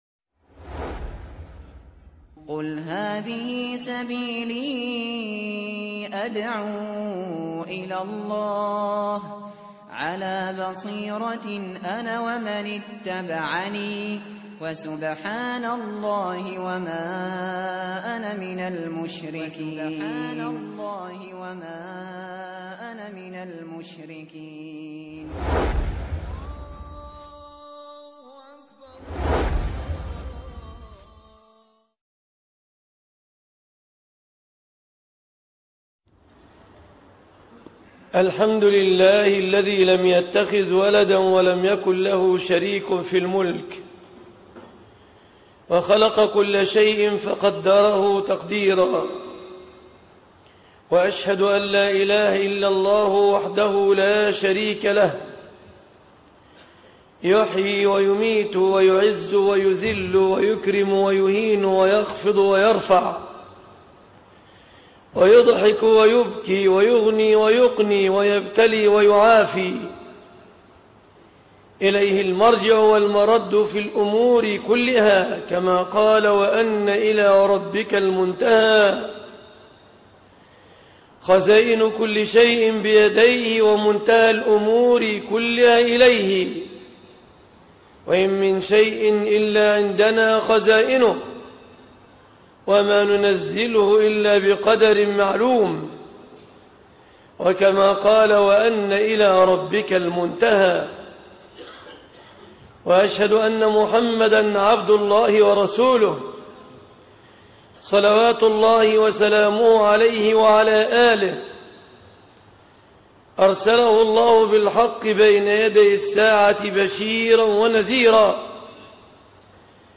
إنما المؤمنون أخوة ( 28/6/2013) خطب الجمعة - فضيلة الشيخ مصطفى العدوي